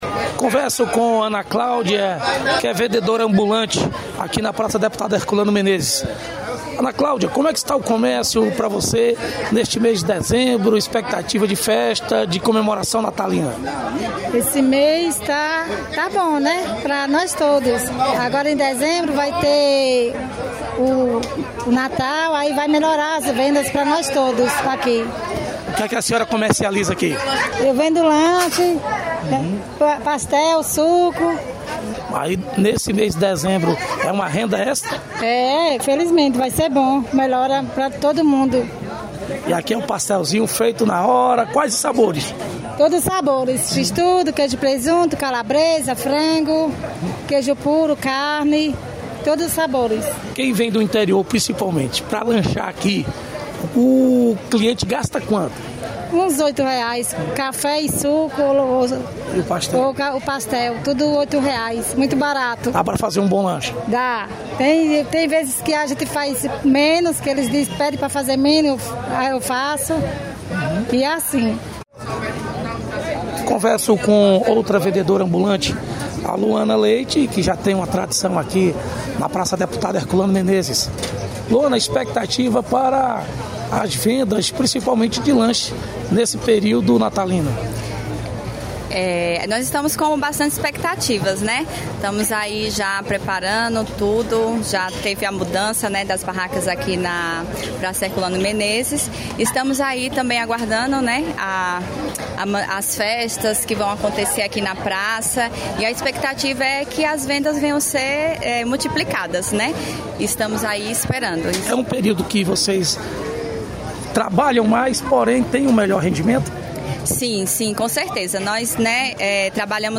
Reportagem com vendedores (ambulantes), de lanches de CFormoso – expectativa de venda para as festas de fim de ano